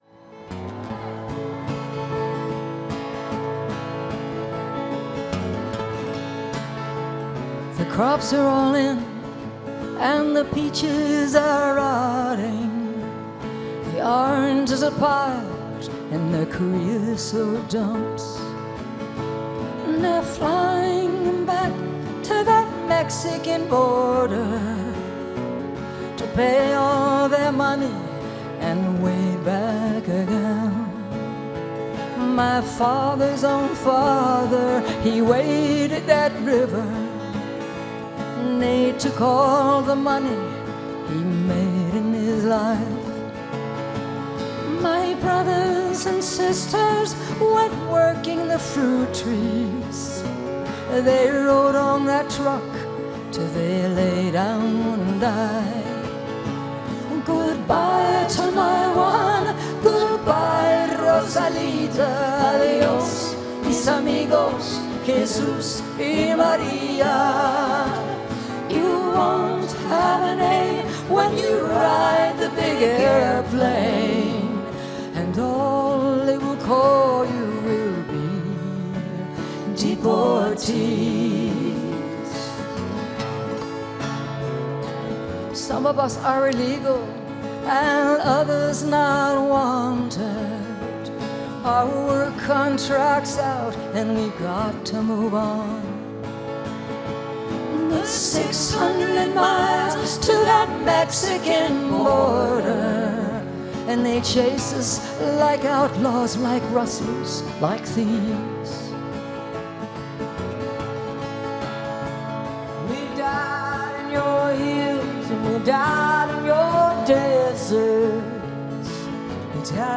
(recorded from youtube)